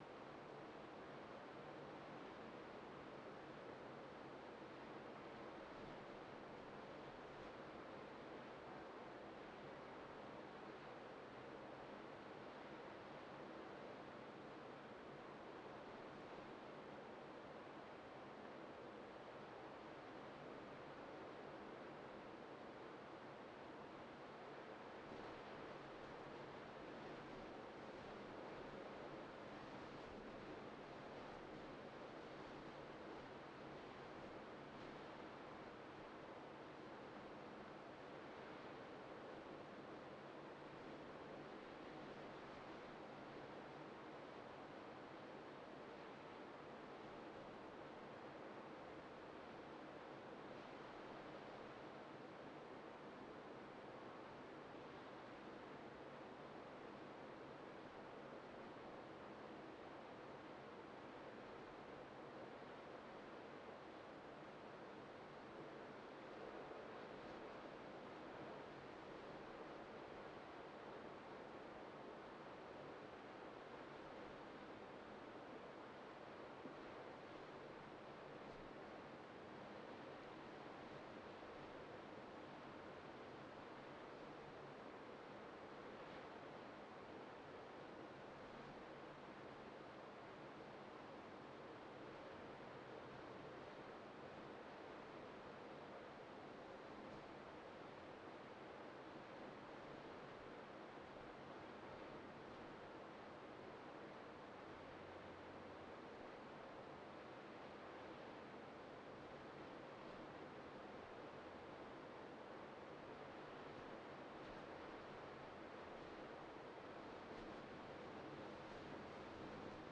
GN00331-Wind_sea_monotonous-wxyz_ambiX.wav